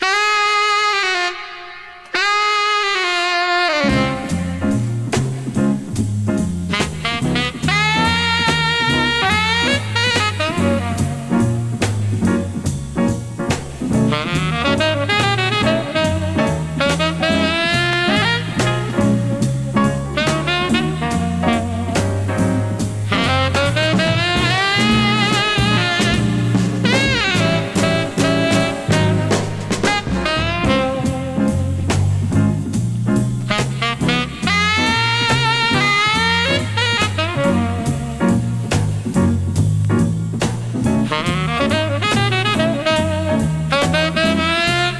こちらは彼の死後に定番楽曲を集めた1枚で、小気味良い演奏が目白押しで、楽しい好盤。
Soul, Rhythm & Blues　USA　12inchレコード　33rpm　Stereo